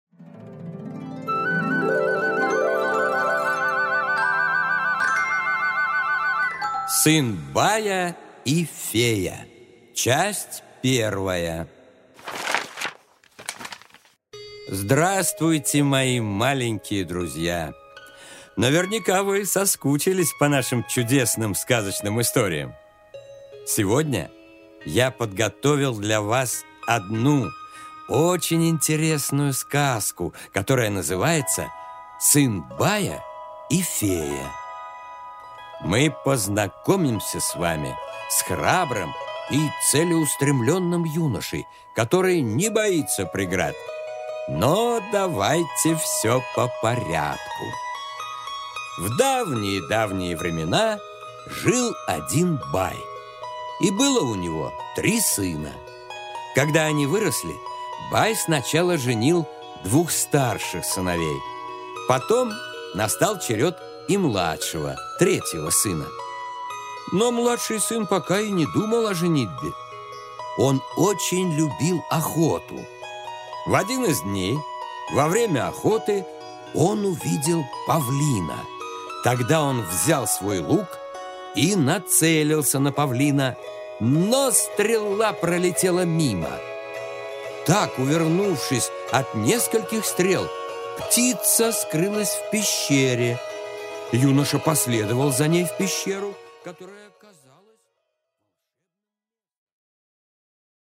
Аудиокнига Сын бая и фея | Библиотека аудиокниг